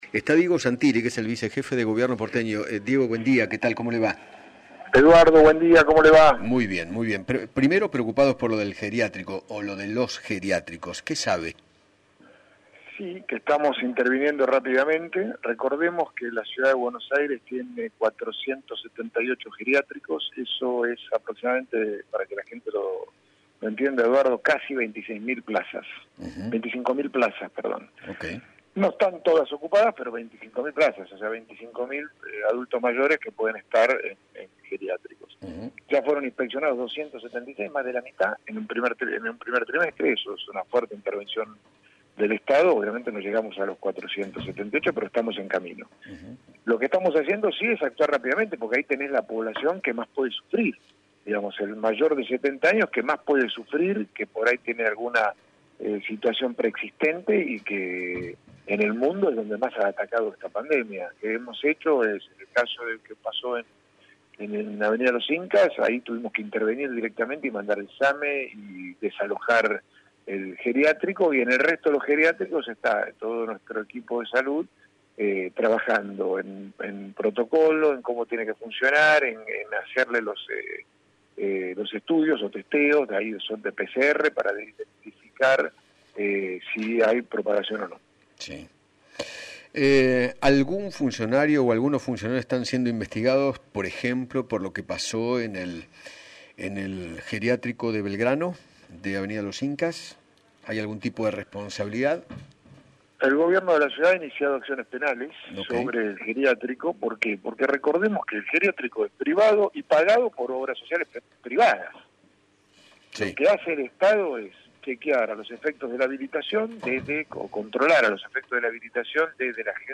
Diego Santilli, Vicejefe de gobierno de la ciudad de Buenos Aires, dialogó con Eduardo Feinmann sobre la implementación de testeos masivos que llevará a cabo tanto el Gobierno de la Ciudad como Nación. Además, habló de lo sucedido en el geriátrico de Belgrano y confirmó que “el Gobierno de la Ciudad ha iniciado acciones penales”.